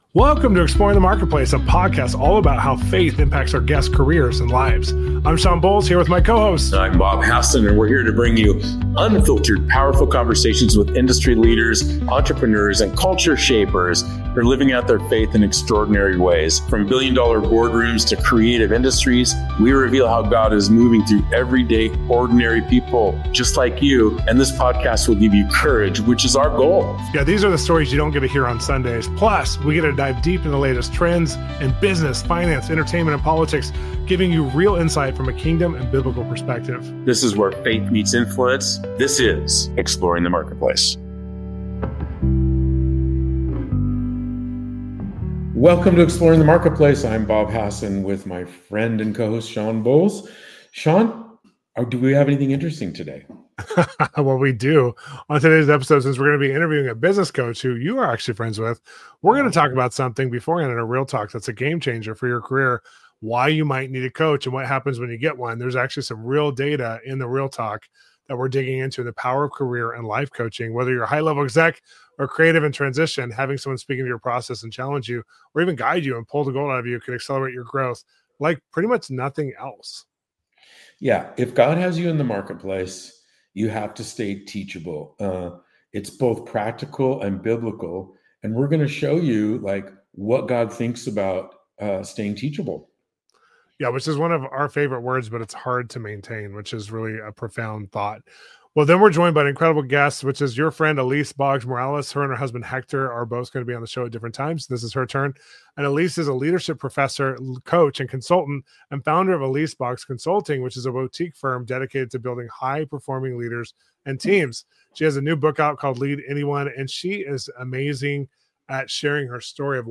This is a conversation about courage, redemption, and marketplace miracles.